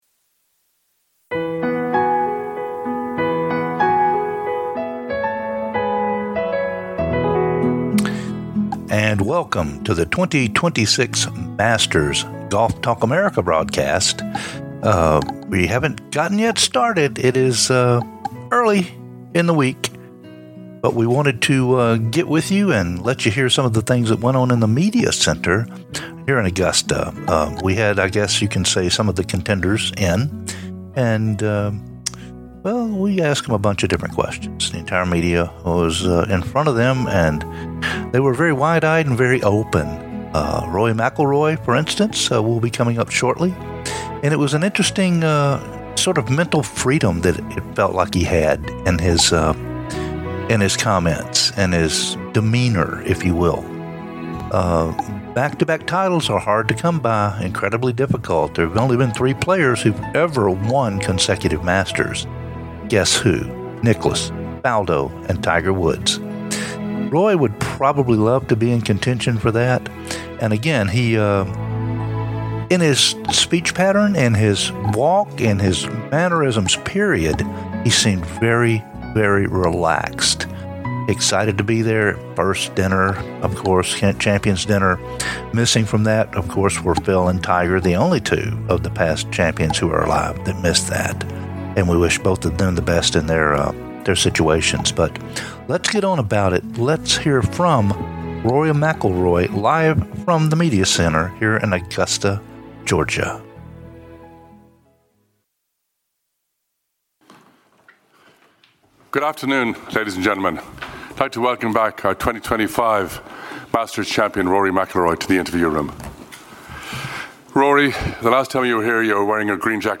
Rory & Bryson join us in the media center at Augusta National Golf Club "LIVE"